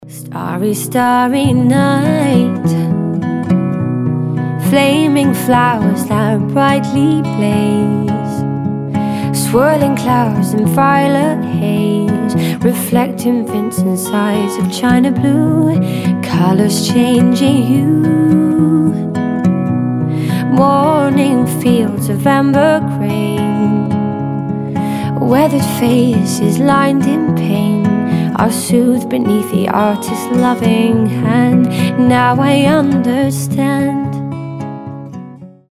гитара
женский вокал
мелодичные
спокойные
красивый женский голос
ballads
нежные
кавер
лиричные